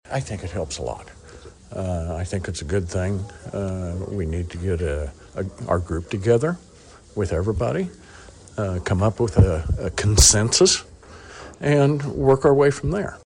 County Commissioner and RDA board representative Doug Peck says this resets the picture in a positive way.